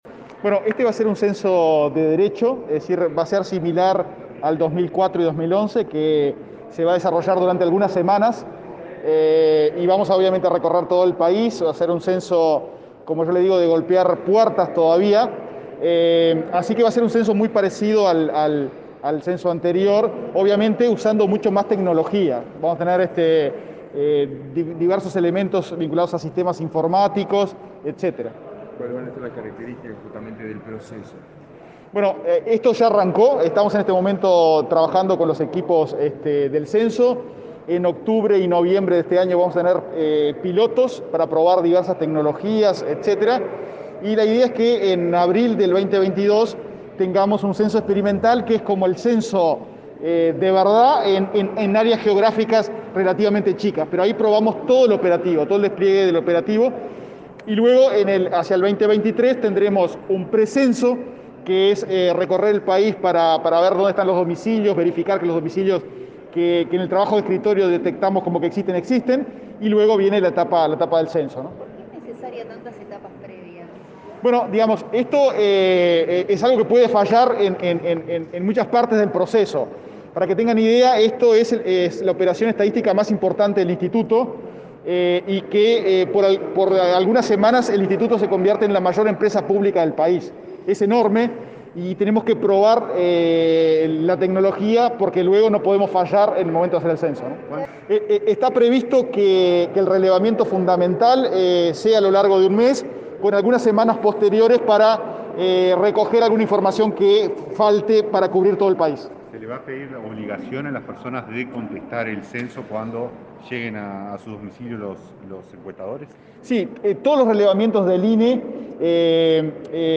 Declaraciones del titular del INE, Diego Aboal